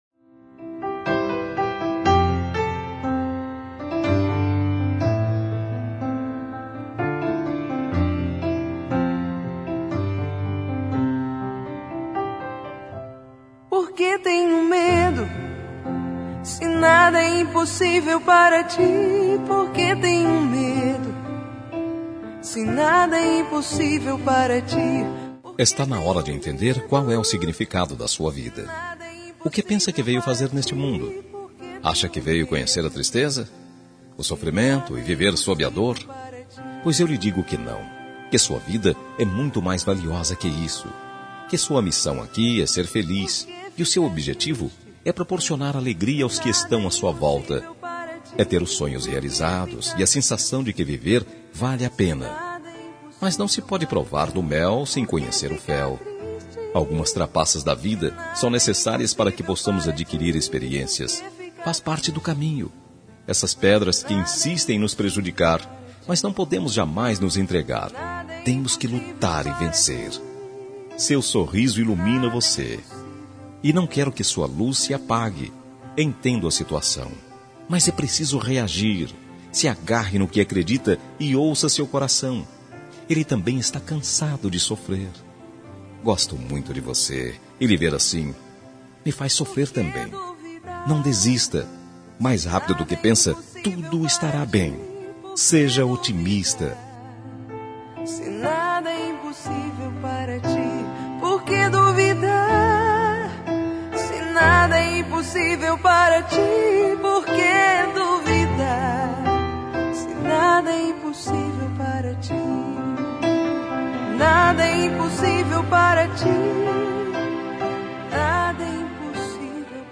Telemensagem de Otimismo – Voz Masculina – Cód: 192 – Bonita